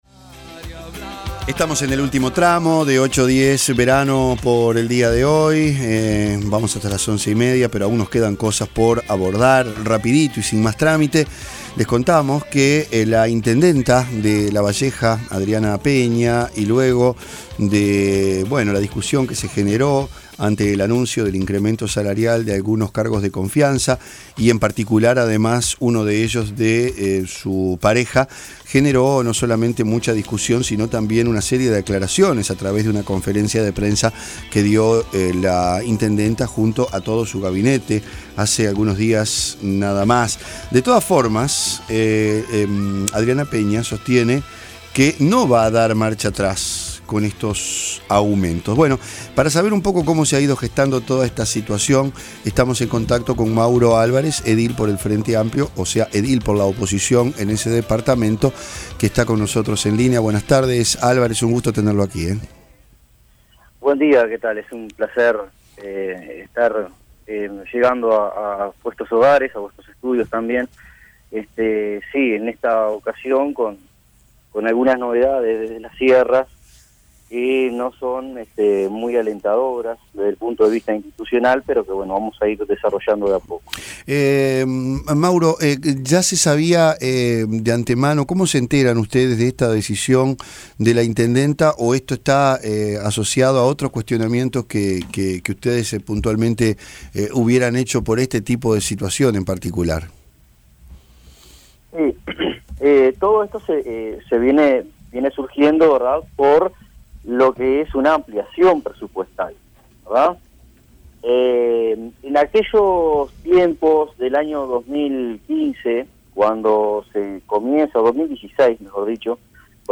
El edil frenteamplista Mauro Álvarez habló en 810 Verano sobre los aumentos salariales que van a tener algunos funcionarios de "particular confianza" de la intendenta de Lavalleja Adriana Peña.